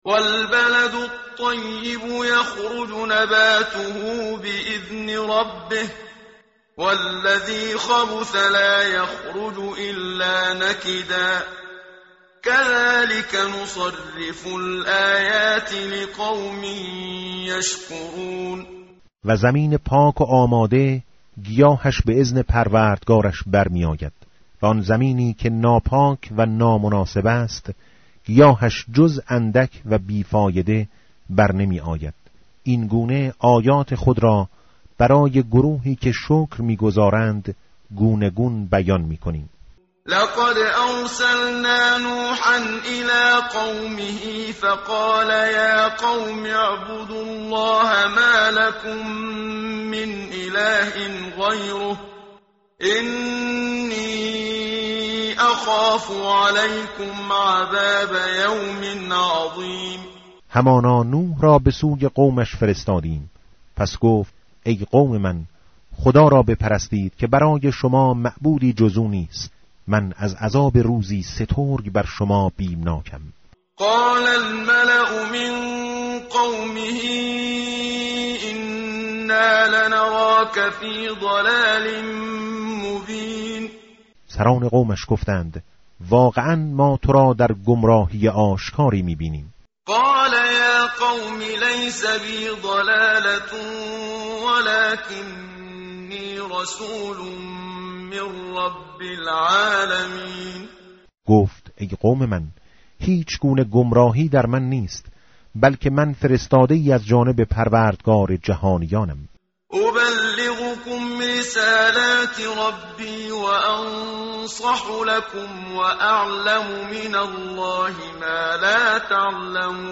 tartil_menshavi va tarjome_Page_158.mp3